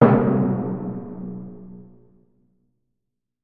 Failure Drum Sound Effect 1
Category 🤣 Funny
cartoon drum error fail failure funny game-over humorous sound effect free sound royalty free Funny